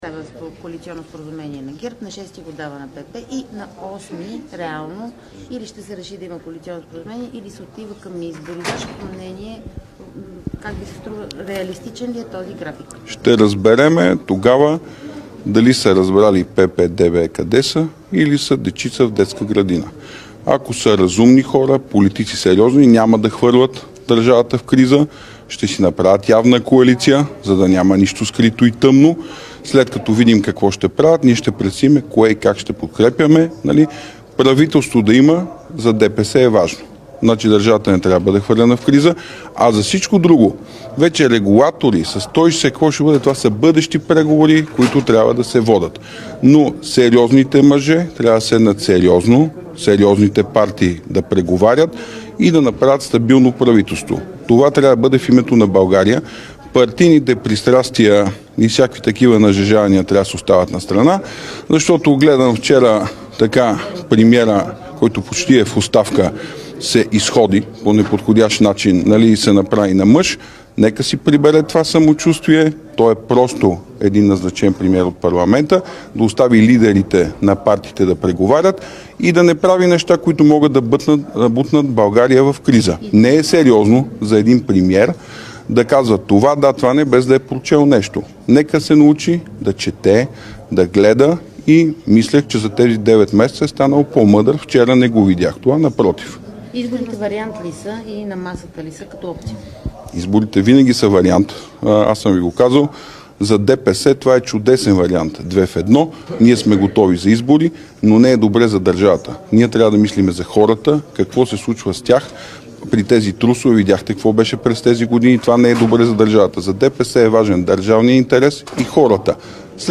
9.15 - Брифинг на лидера на ГЕРБ Бойко Борисов за коалиционното споразумение и ротацията. - директно от мястото на събитието  (Народното събрание)
Директно от мястото на събитието